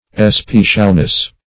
Especialness \Es*pe"cial*ness\, n.